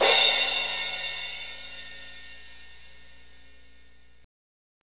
Index of /90_sSampleCDs/Northstar - Drumscapes Roland/CYM_Cymbals 3/CYM_H_H Cymbalsx